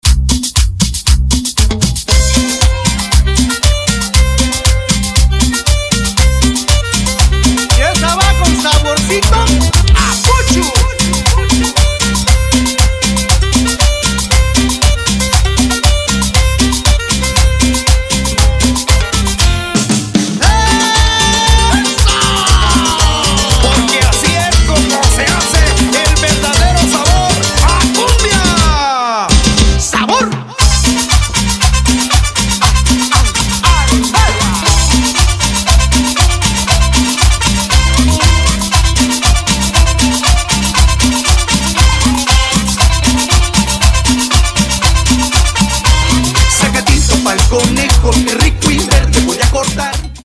Dive into the pulsating rhythms and energy of Latin music
DJ